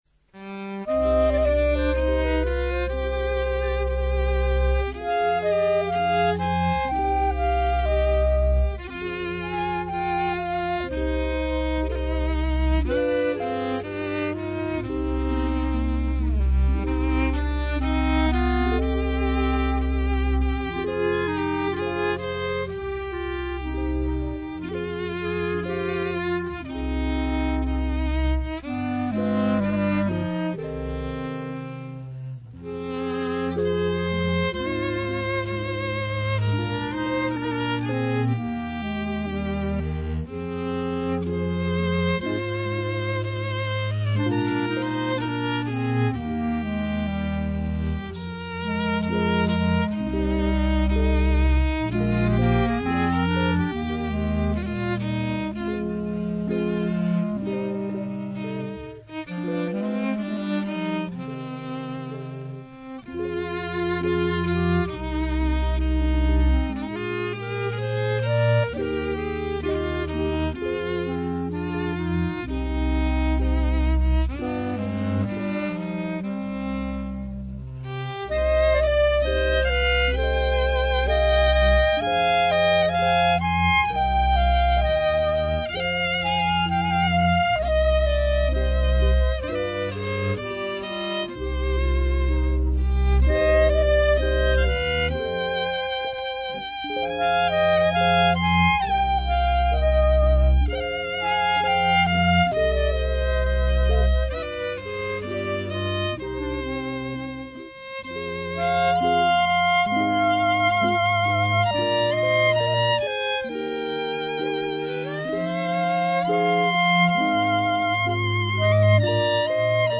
Music Yiddish, Klezmer
Hot Azoï Groupe de Musique Yiddish Klezmer
Records are played as a trio however upon request we are able to perform with 4 to 5 musicians.